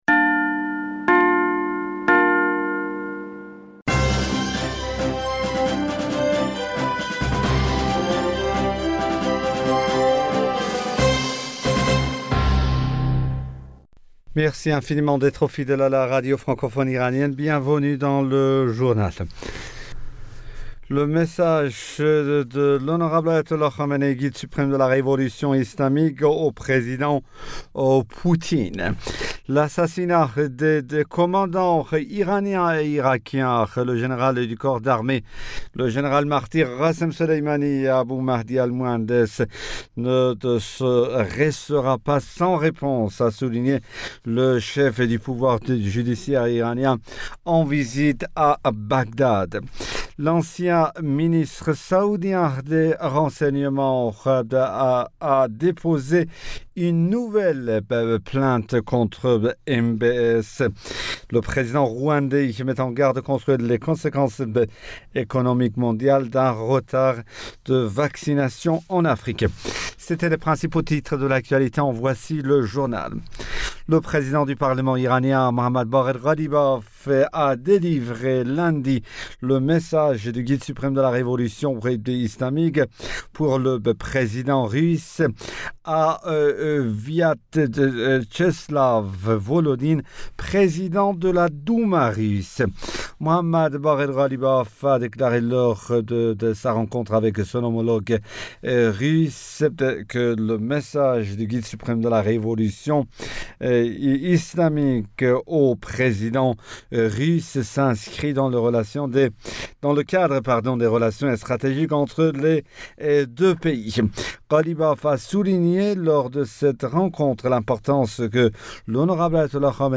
Bulletin d'informationd u 09 Février 2021